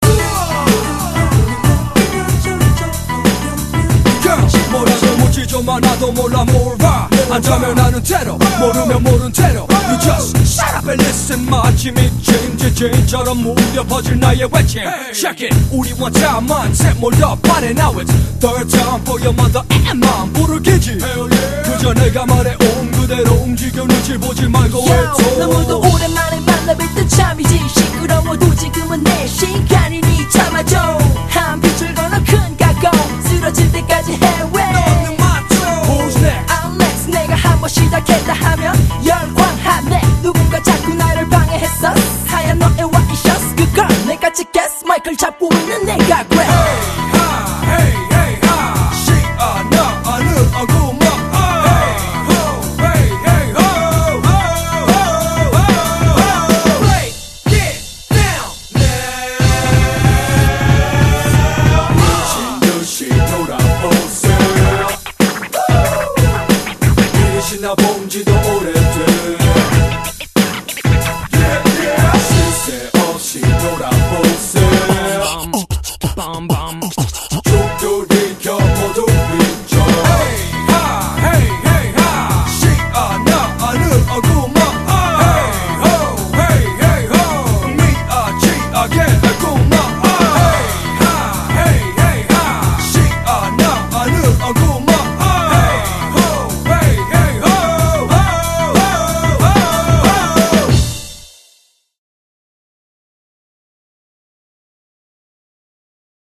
BPM93--1
Audio QualityPerfect (High Quality)